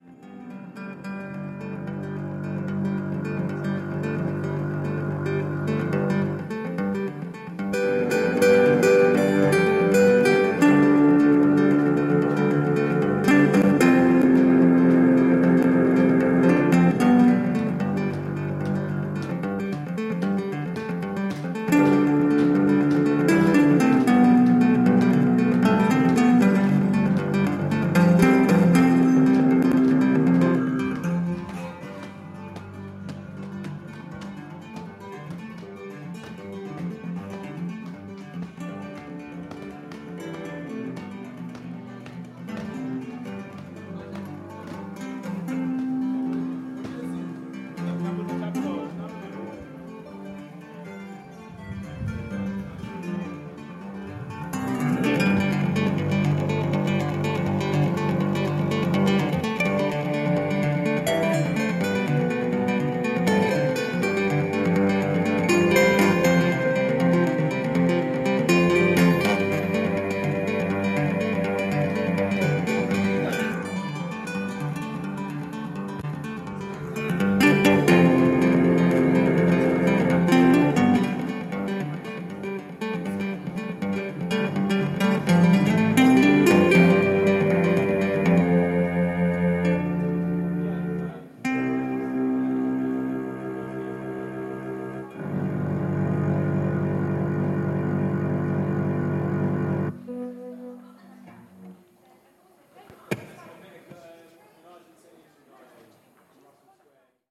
Waterloo Station
Field recording